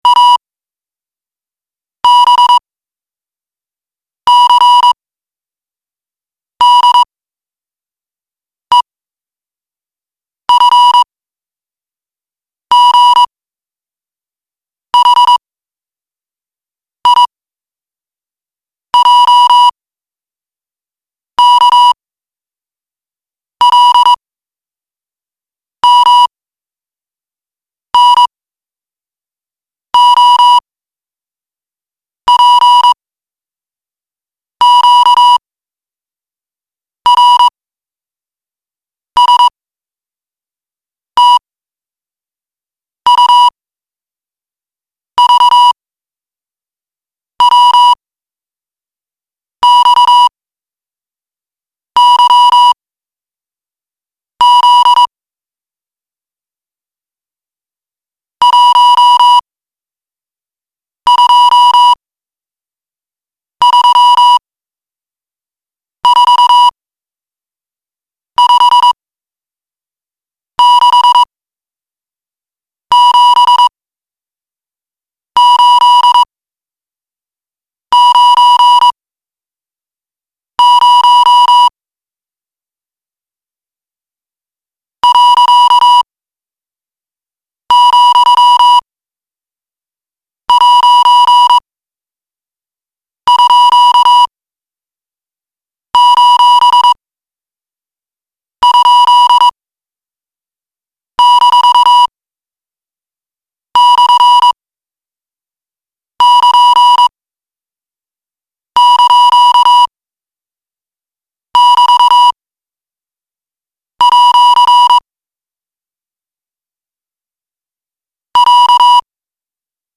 The Morse Code alphabet